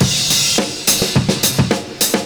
106CYMB04.wav